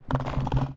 Rattle2.ogg